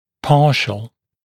[‘pɑːʃl][‘па:шл]частичный, неполный